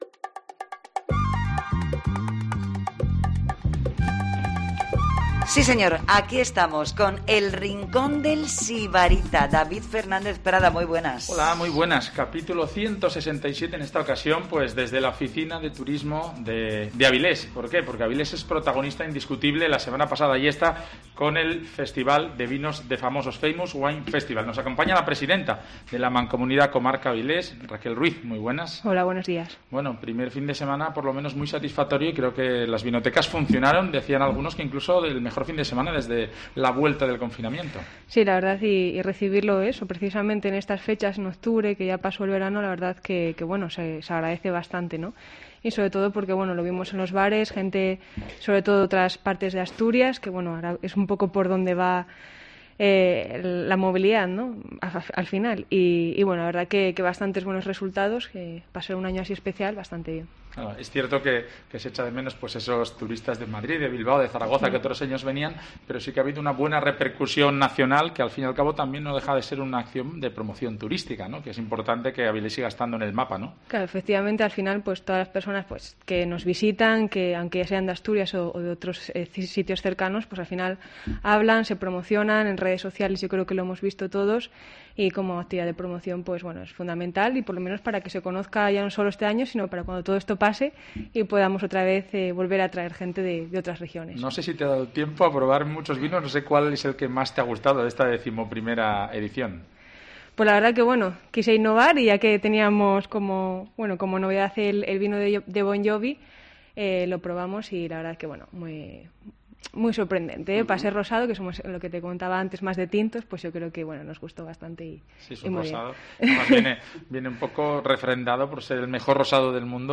El Rincón del Sibarita desde Avilés